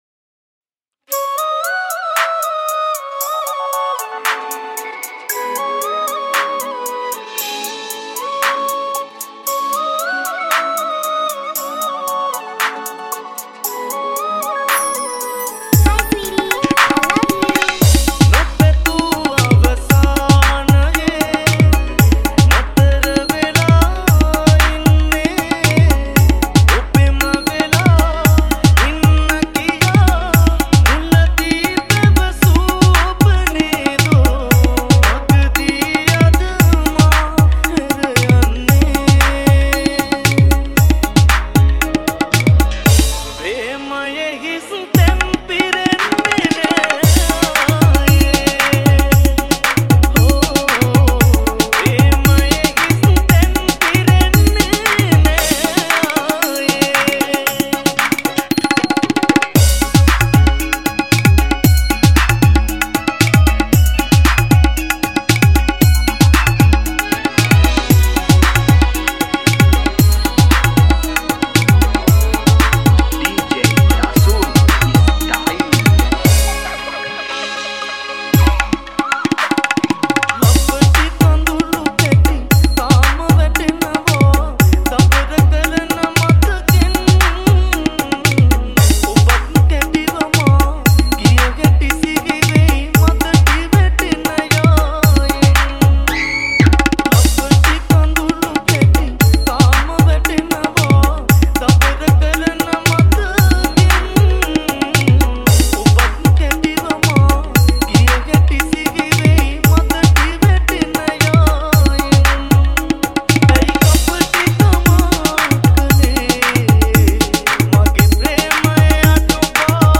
Thabla Dj Remix